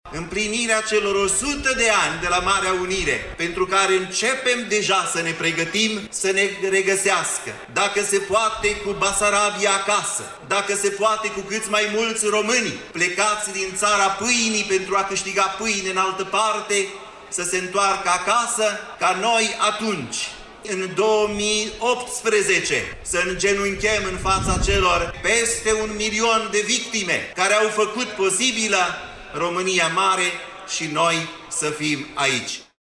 În discursul rostit cu prilejul Zilei Naționale, mitropolitul Moldovei și Bucovinei, Înalt Prea Sfințitul Teofan, a transmis un mesaj de speranță: